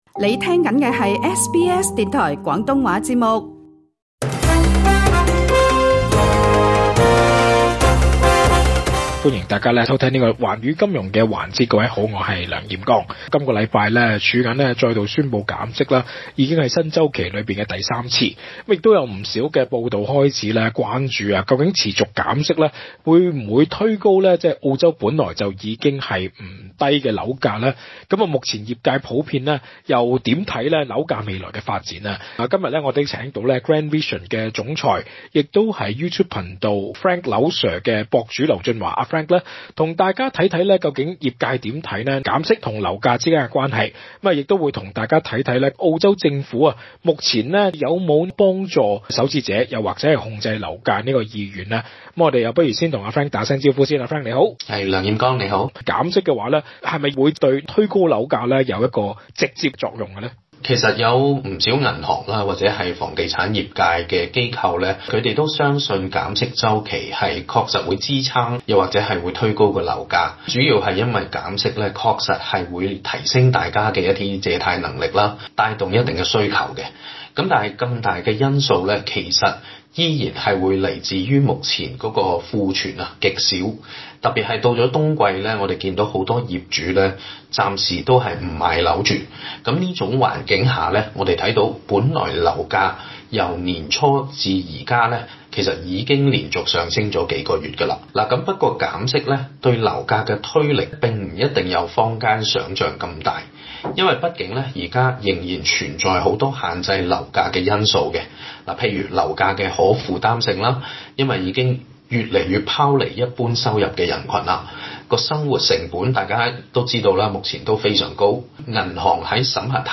詳情請收聽完整錄音訪問